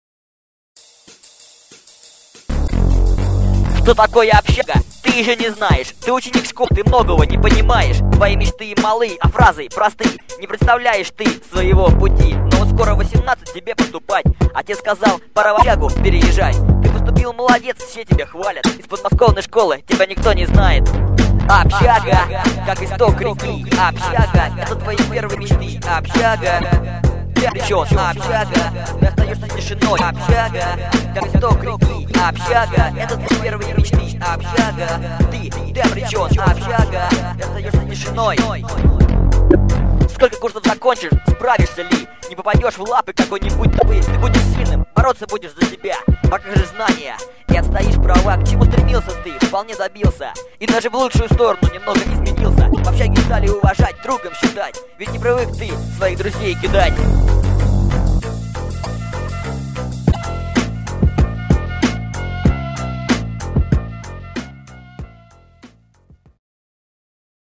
быстрая версия